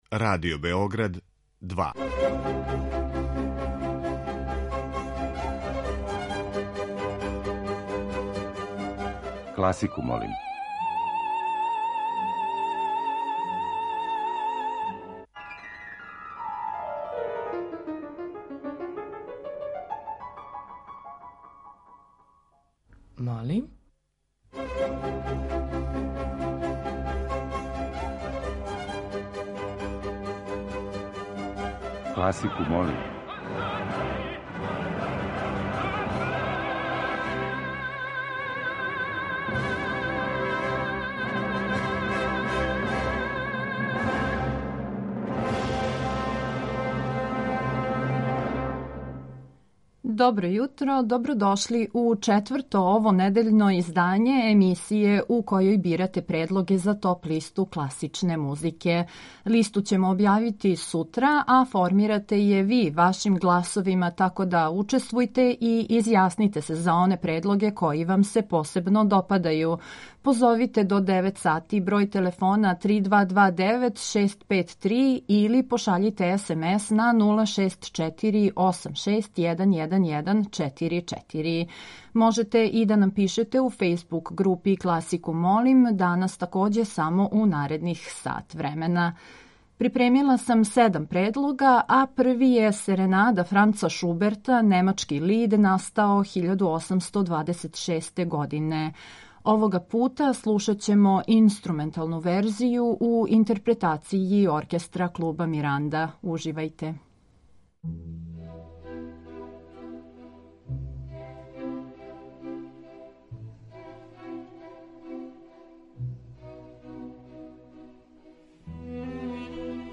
И ове седмице, од понедељка до четвртка емитоваћемо разноврсне предлоге композиција класичне музике.